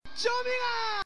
男人大喊救命音效免费音频素材下载